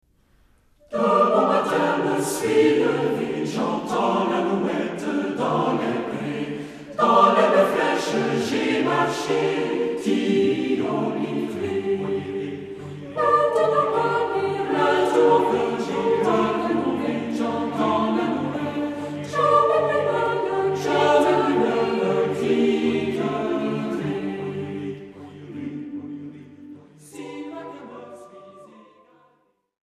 Tonalité : sol majeur ; sol mineur